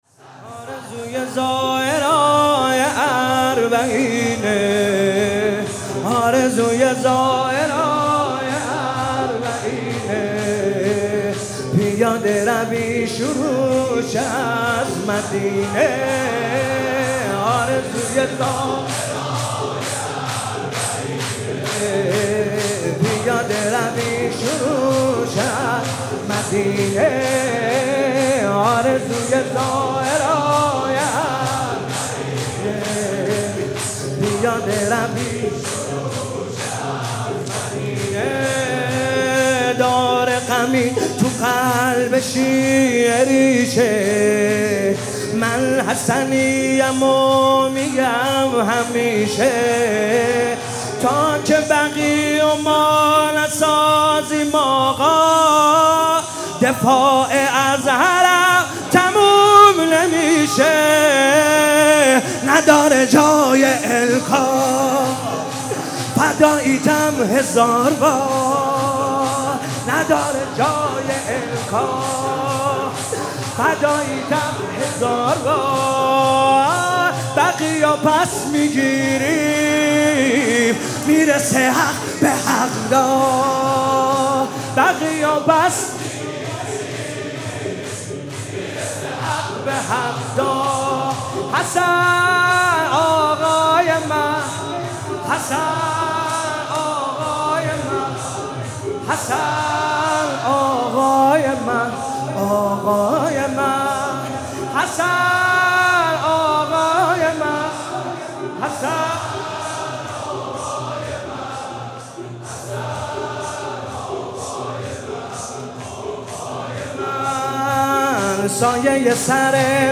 محرم 97 شب ششم
شور